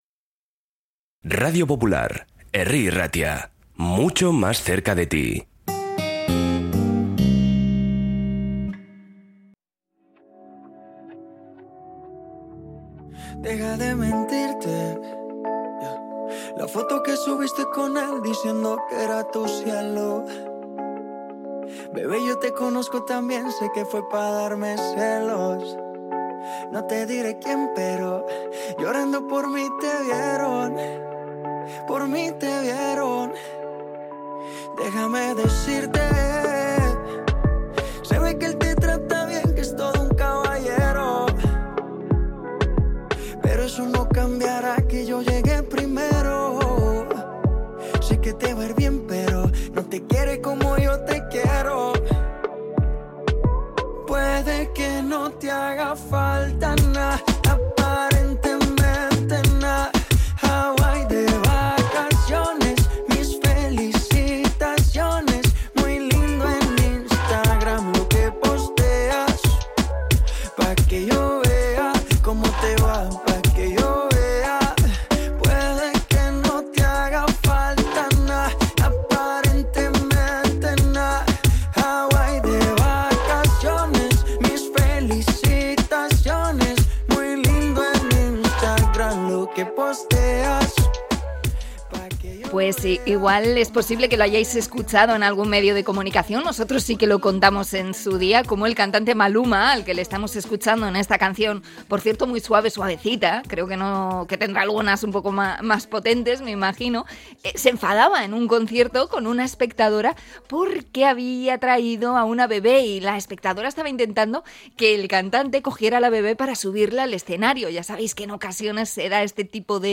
entrevista con pediatra por los ruidos de los conciertos con bebés